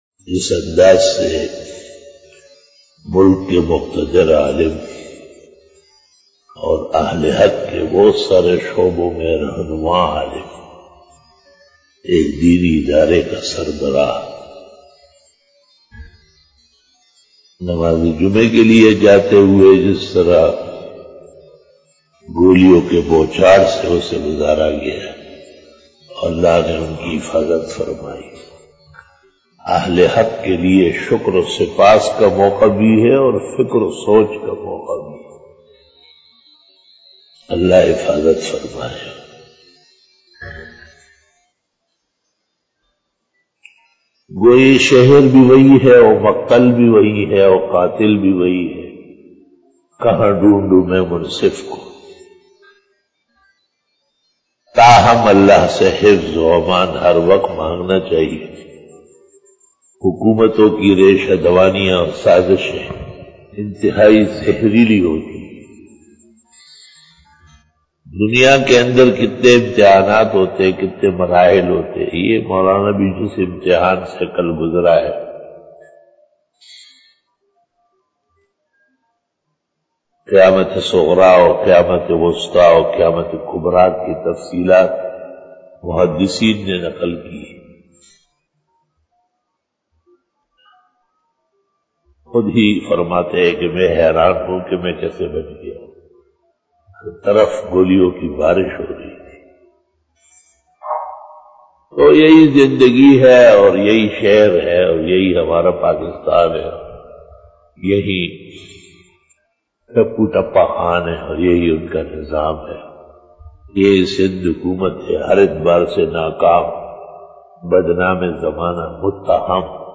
After Namaz Bayan